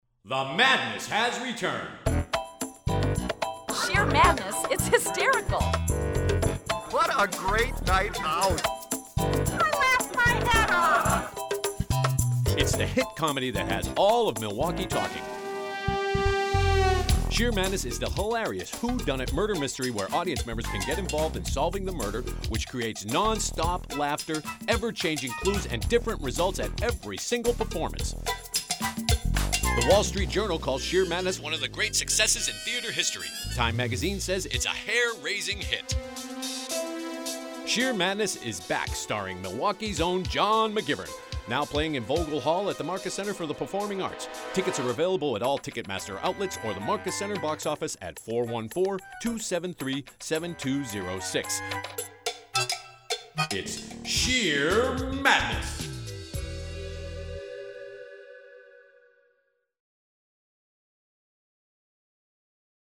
Shear Madness Radio Commercial